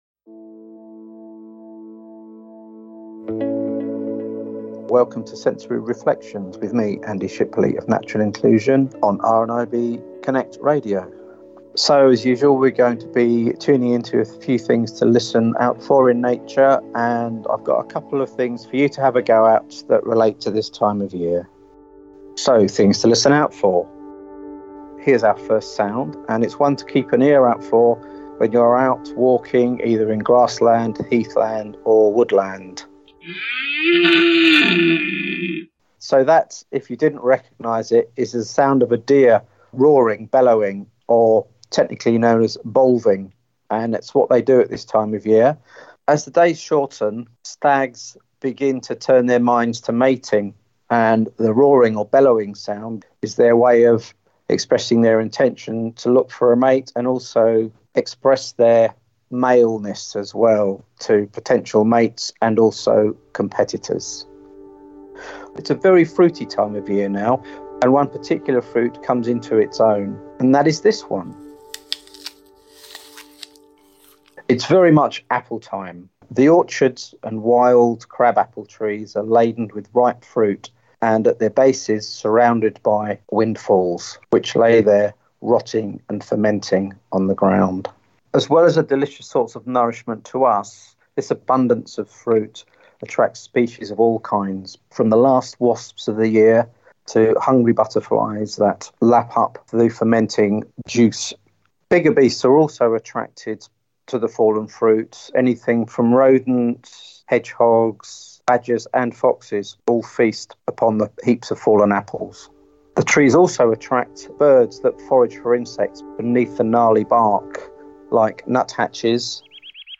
This month, some things to keep an ear out for when out walking near heathland, grassland or woodland. With special appearance by: red dear stags, wild boar, and apples!